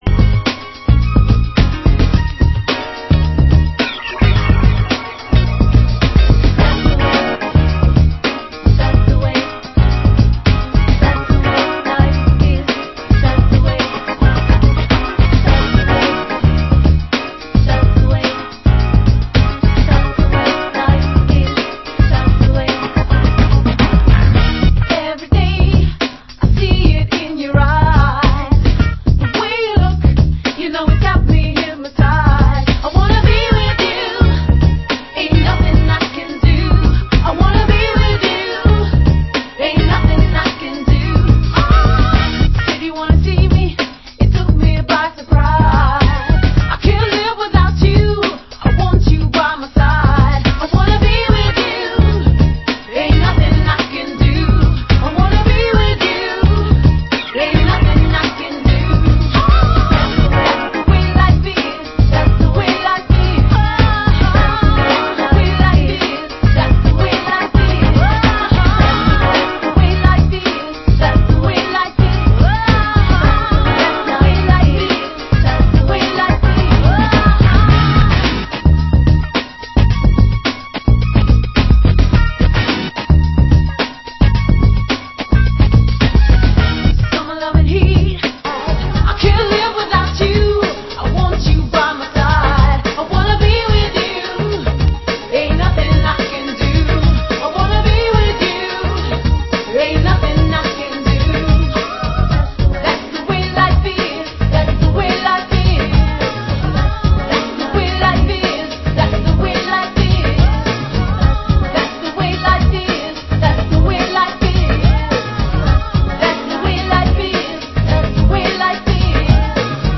Genre: Down Tempo